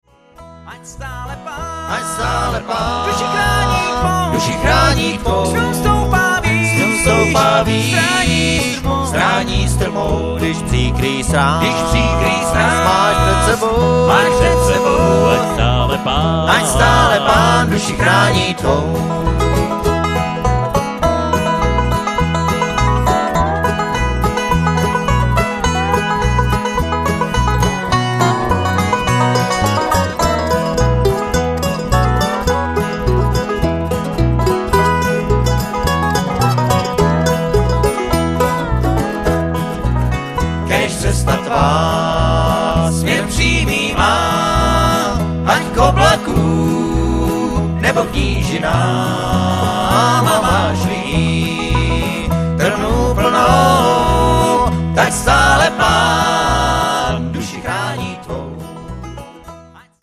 Guitar
Banjo
Mandolin
Dobro
Electric Bass